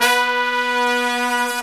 LONG HIT02-R.wav